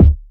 • Smooth Kickdrum Sound D Key 10.wav
Royality free bass drum single shot tuned to the D note. Loudest frequency: 136Hz
smooth-kickdrum-sound-d-key-10-Io3.wav